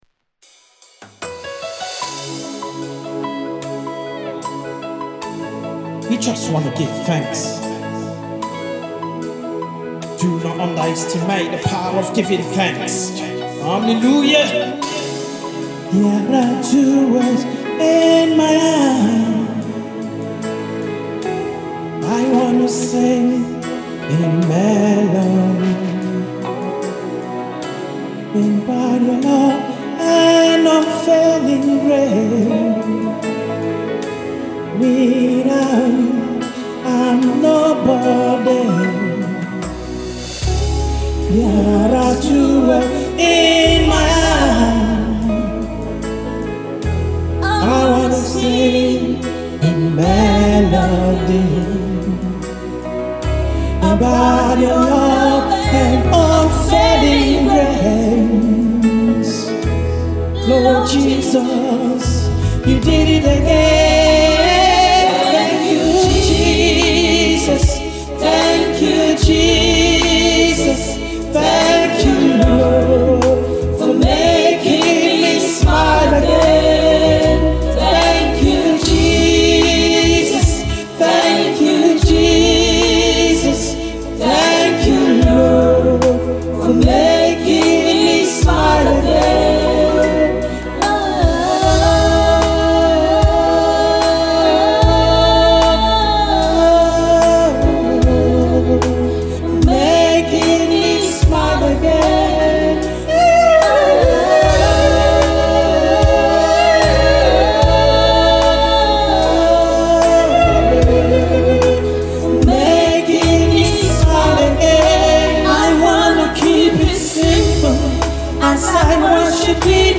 Emerging Gospel artist